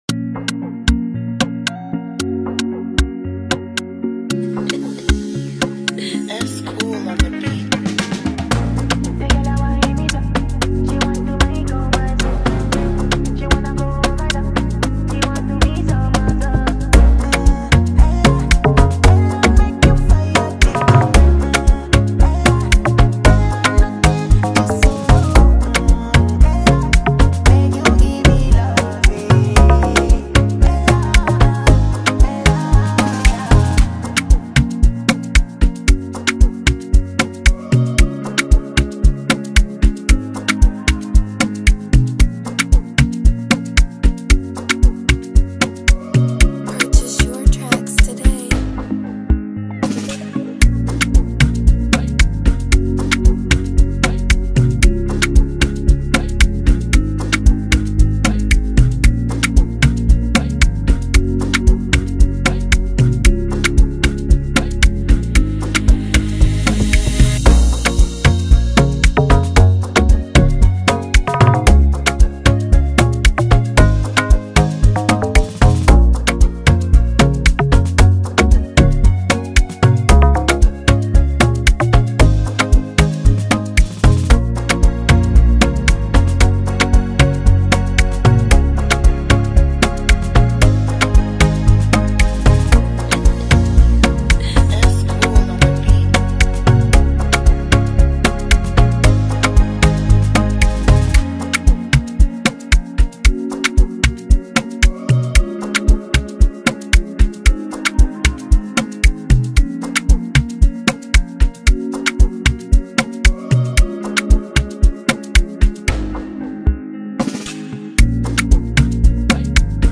Afrobeat instrumentals
With its pulsating rhythm and melodic charm